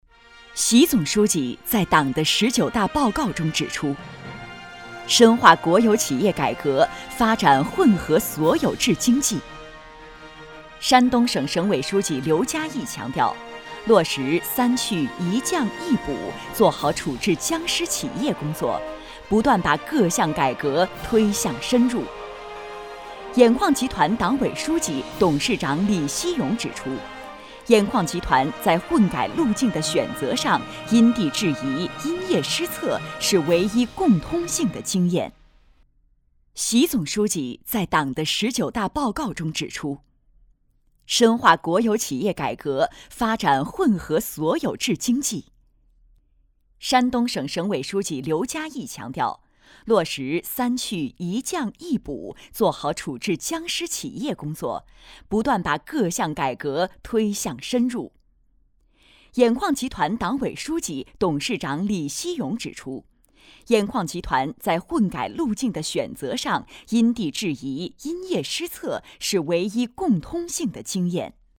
专题女52号（国企纪实大气
激情力度 企业专题
品质女声，双语配音，大气稳重，磁性，甜美，讲述等不同风格。